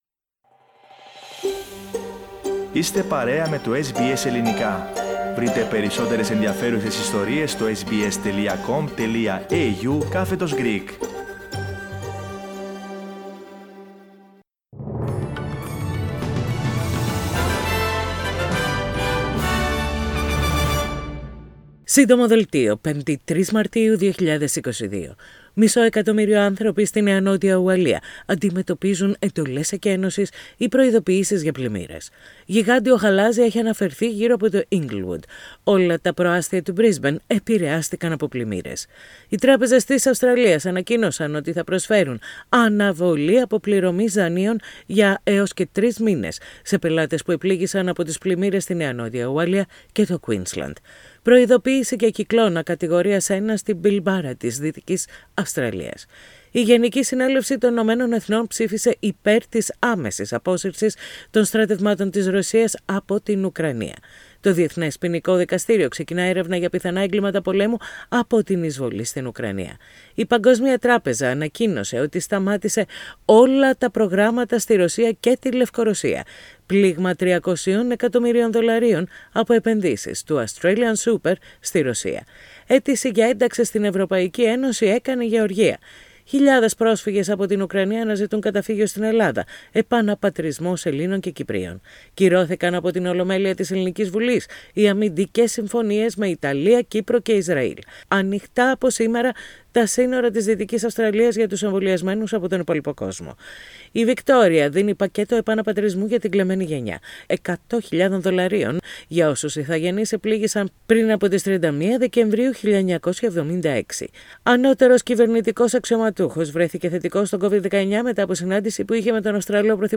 News in Greek. Source: SBS Radio